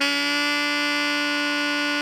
Index of /90_sSampleCDs/Giga Samples Collection/Sax/ALTO 3-WAY
ALTO GR D 3.wav